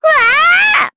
Miruru-Vox_Damage_jp_03.wav